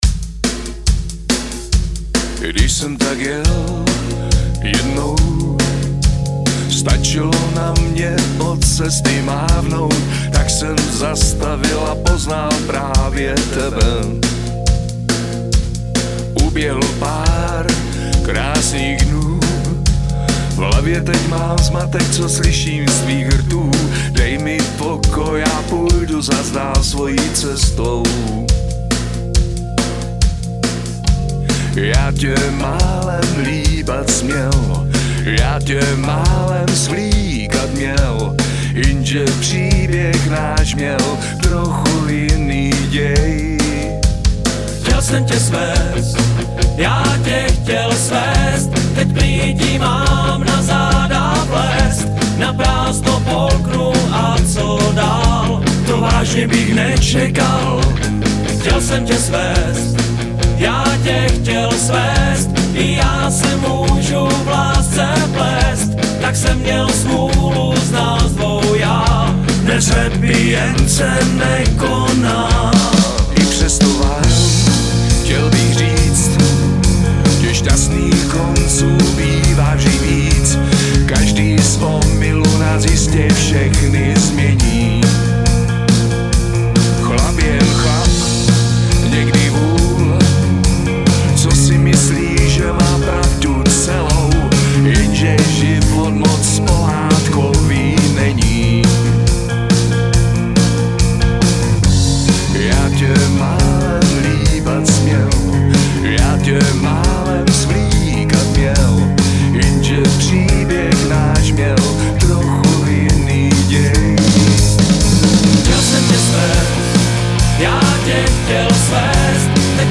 zpěv, řev, sípot
bicí
kytara, zpěv
klávesy, zpěv